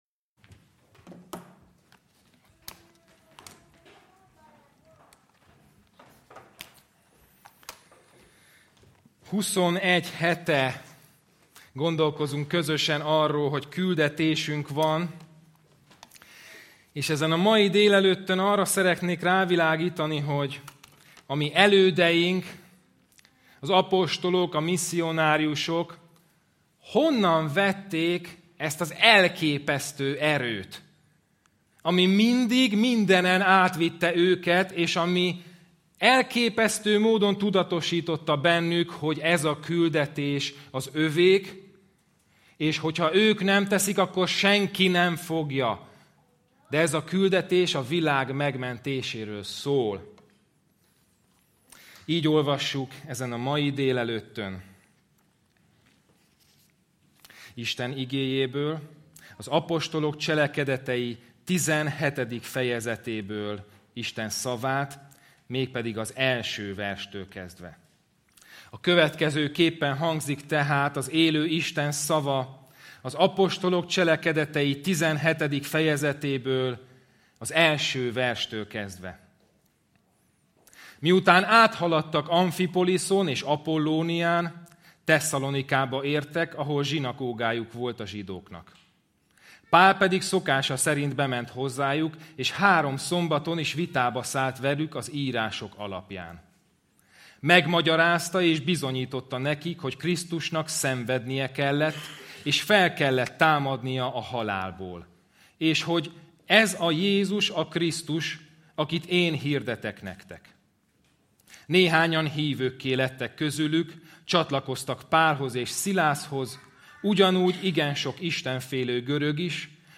Passage: ApCsel 17,1-15 Service Type: Igehirdetés